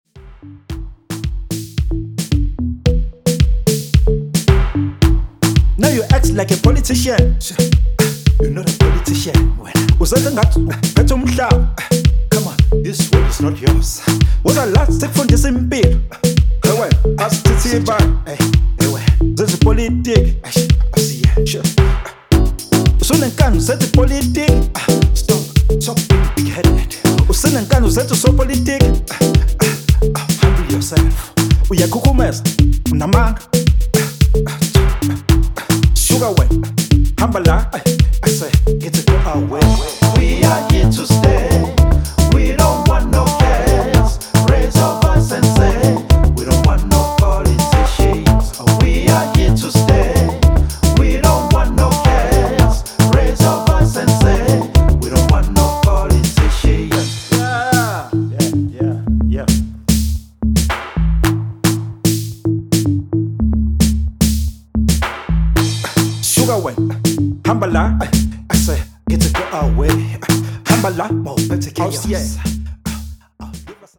Zulu staccato rap and R&B stylings